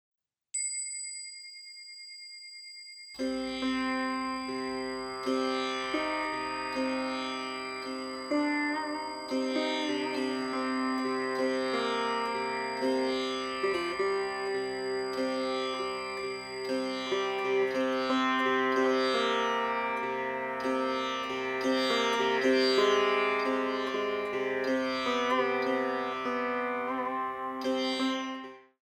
Sitar, Handsonic Tabla